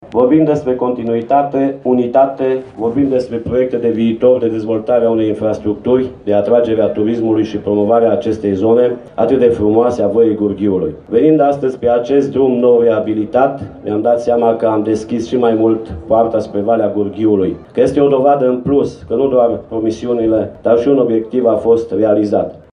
Evenimentul a ajuns la cea de-a zecea ediție, și a reunit la sfârșit de săptămână câteva mii de oameni pe Platoul ”La Fâncel” din comuna Ibănești.
Primarul comunei Gurghiu, Laurențiu Boar, a subliniat importanța festivalului pentru turismul local, ajutat și de asfaltarea drumului până la locul evenimentului.